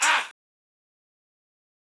SouthSide Chant (20).wav